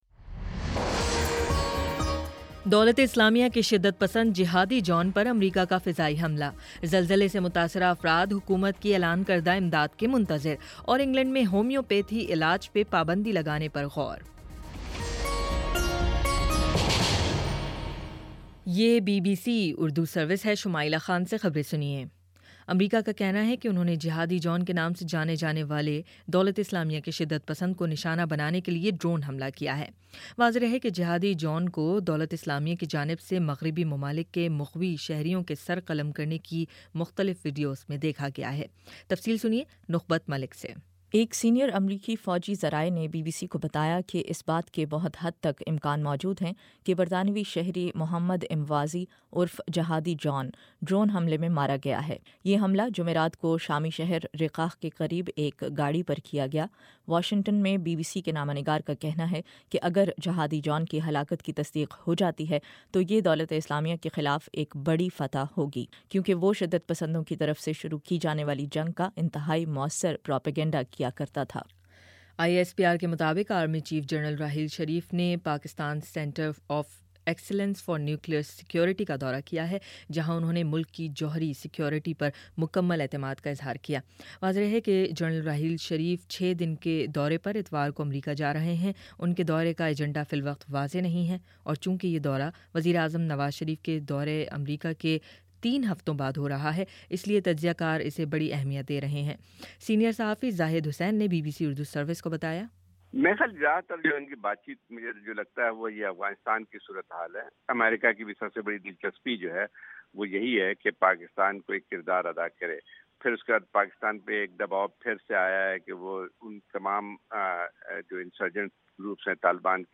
نومبر 13 : شام چھ بجے کا نیوز بُلیٹن